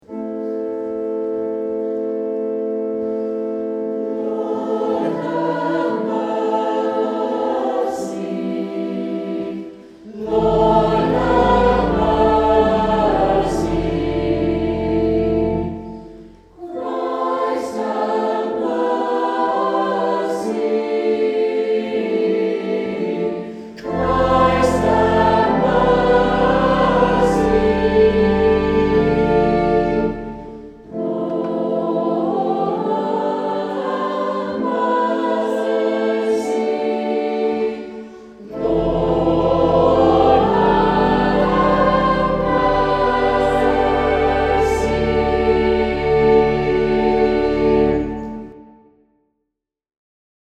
Saint Clement Choir Sang this Song